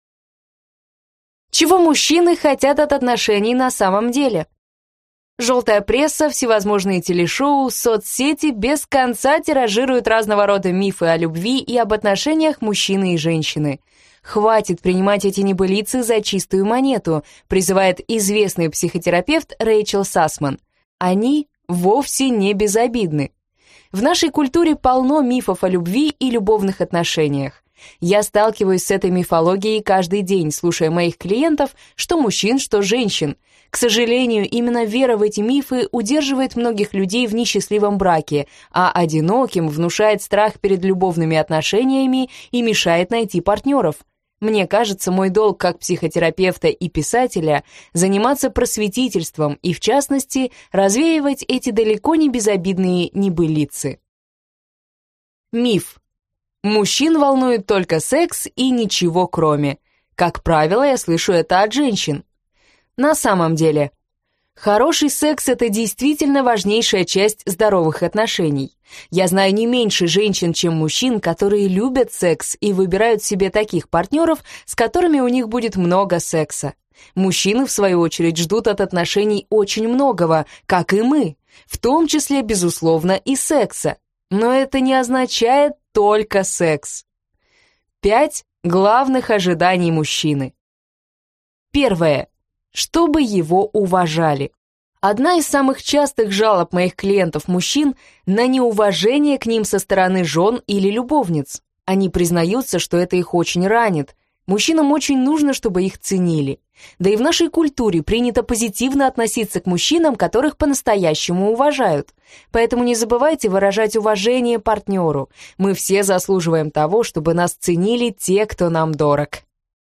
Аудиокнига О чём не говорят мужчины, или Что мужчины хотят от отношений на самом деле | Библиотека аудиокниг